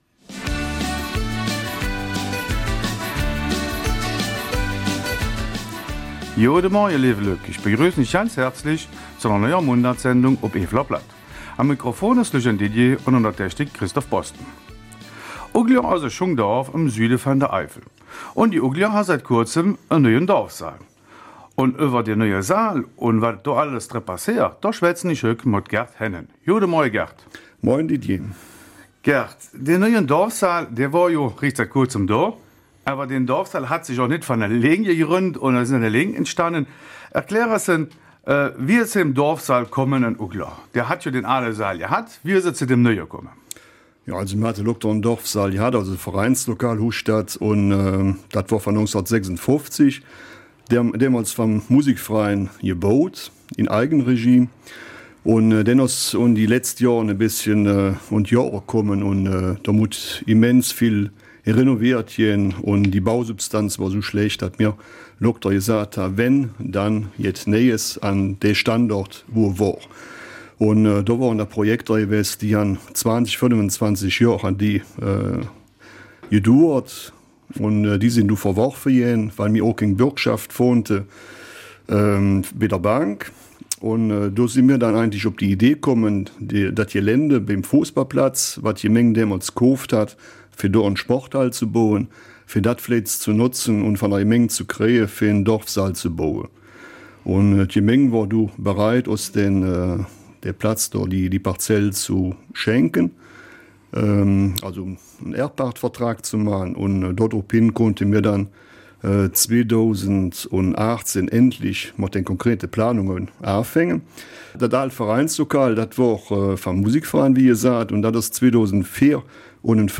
Eifeler Mundart: Dorfsaal Oudler - Partylaune und Karnevalsstimmung